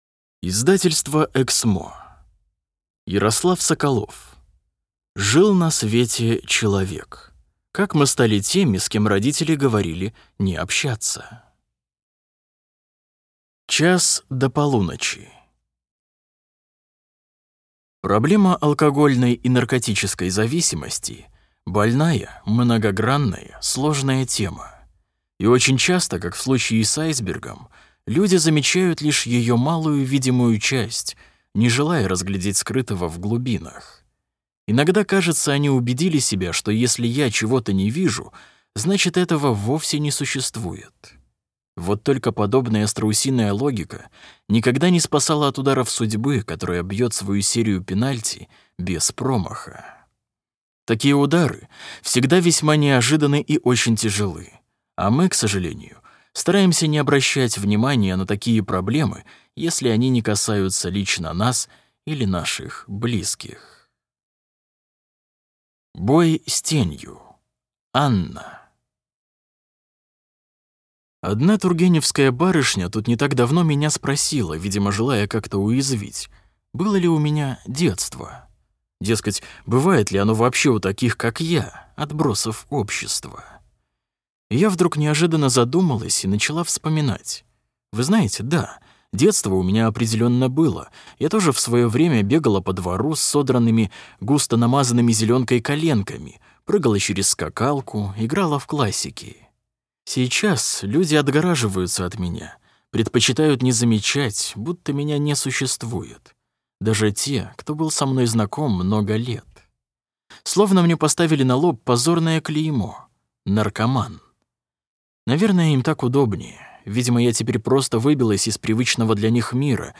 Аудиокнига Жил на свете человек. Как мы стали теми, с кем родители говорили не общаться | Библиотека аудиокниг